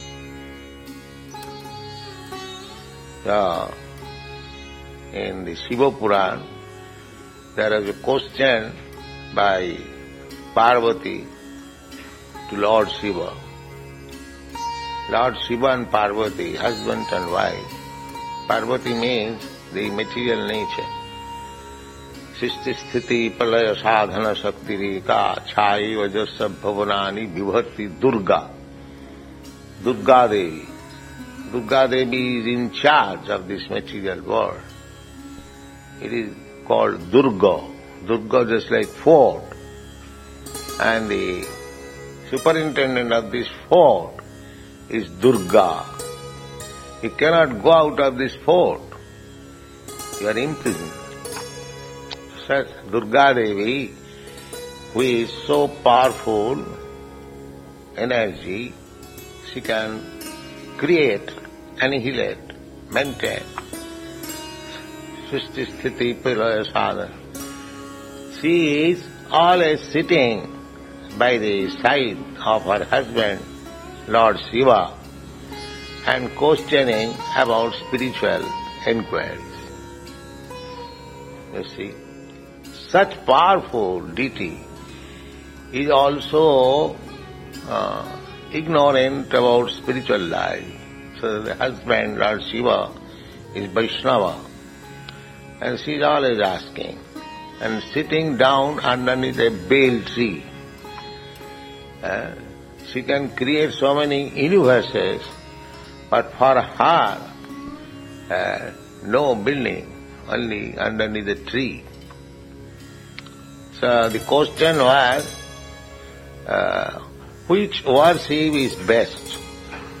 (720821 - Lecture SB 01.02.18 - Los Angeles)